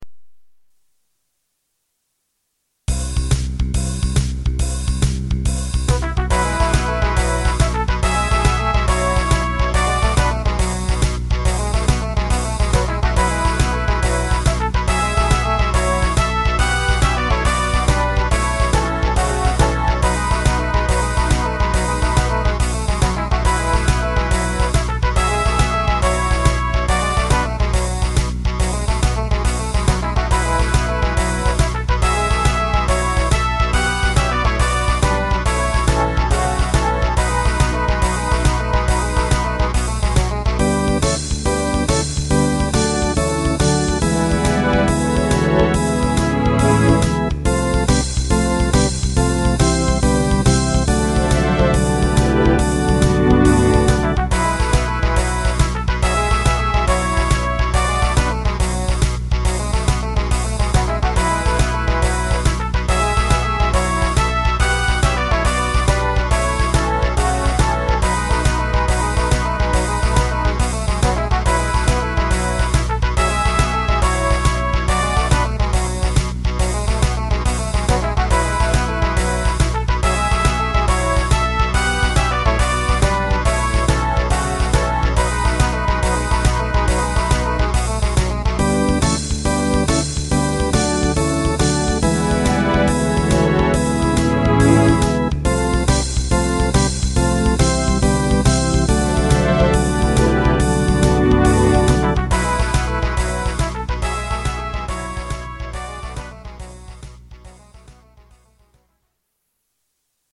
管理人が作ったMIDI集です
後半部分の楽器の選び方は、超適当でございます・・・。